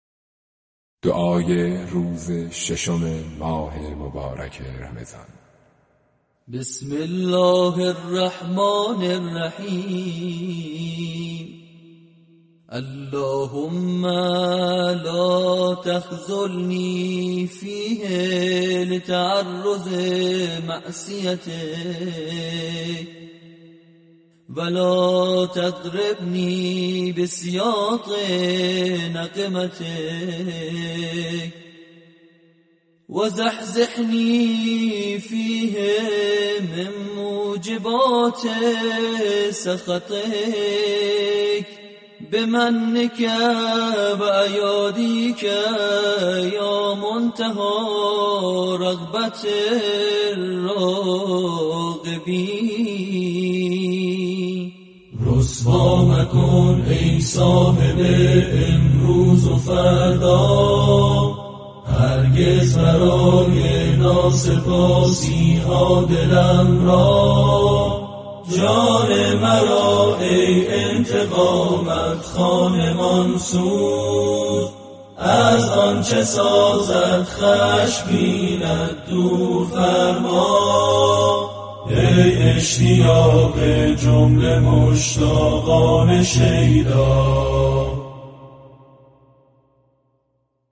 برچسب ها: خبرگزاری قرآن ، خبرگزاری ایکنا ، چندرسانه ای ، دعای روز ششم ، ماه مبارک رمضان ، ادعیه خوانی ، صوت ، پنجشنبه ، iqna